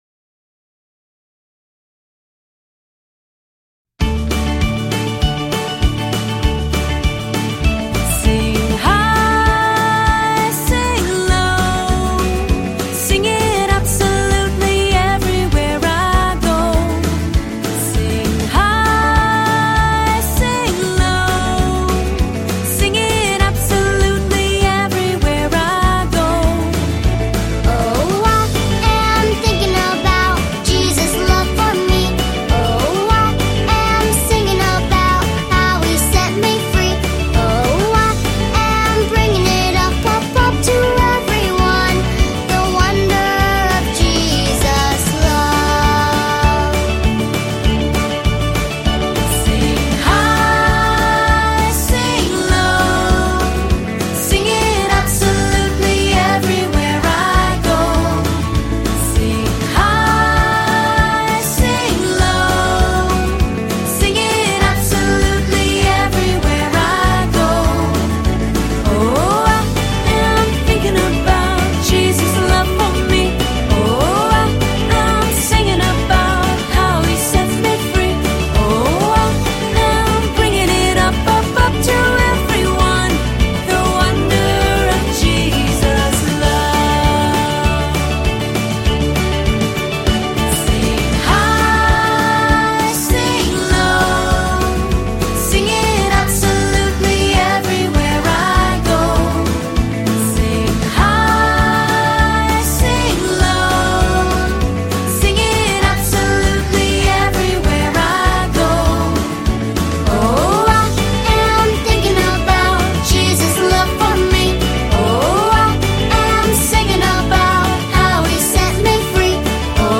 动作跟唱 | High and Low (视频+音频）
视频里有动作演示，音频里歌会自动重复三遍。